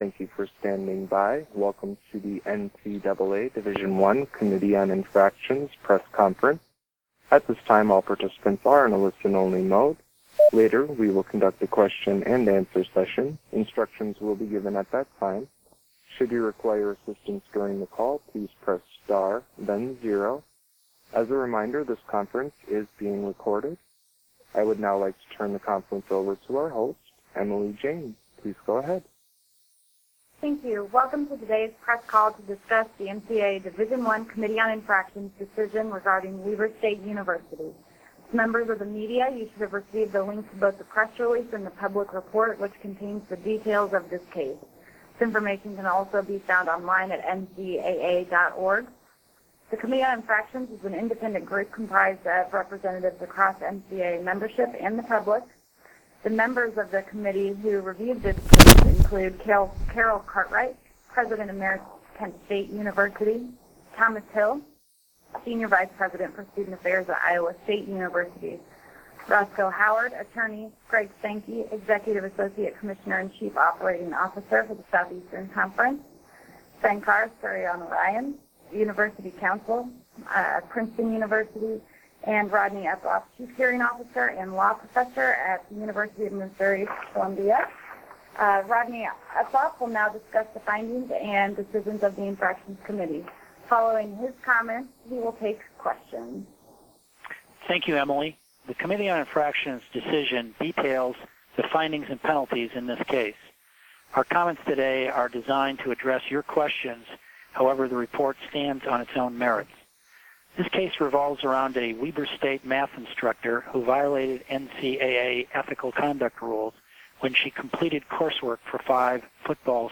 Media Teleconference Recording - NCAA Division I Committee on Infractrions -Weber State University